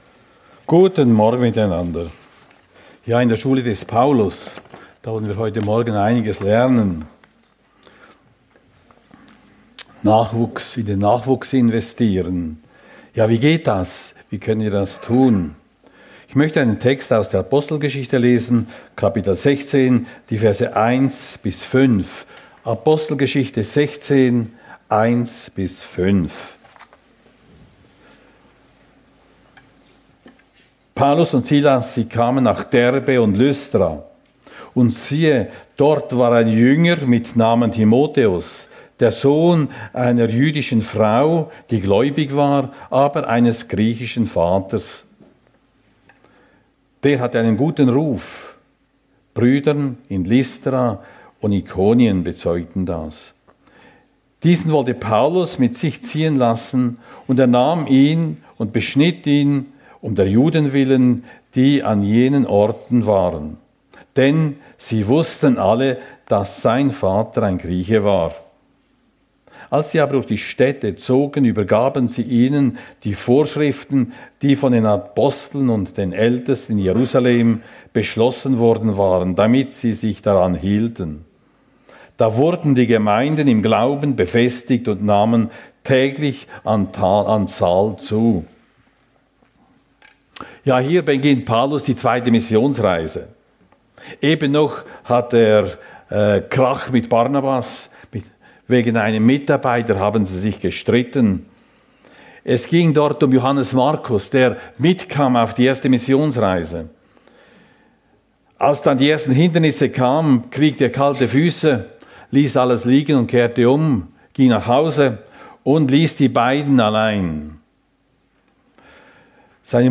Apostelgeschichte 16,1-5 ~ FEG Sumiswald - Predigten Podcast